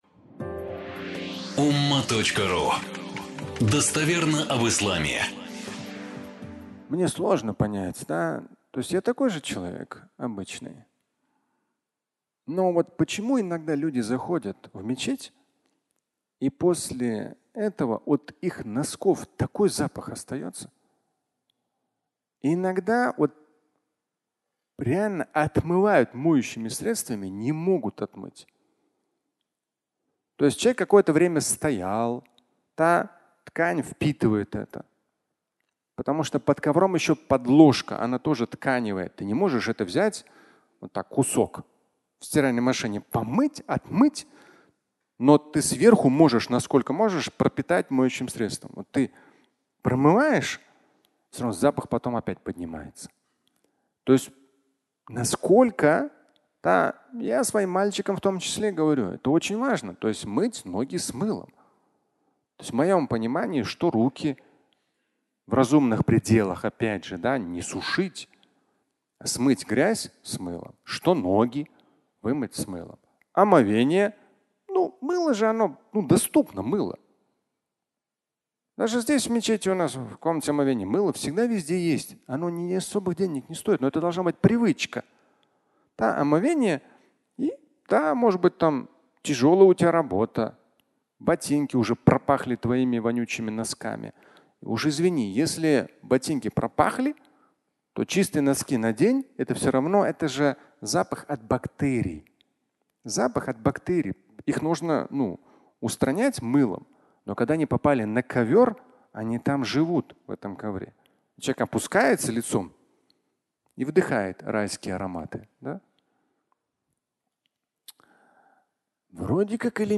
Носки и атеист (аудиолекция)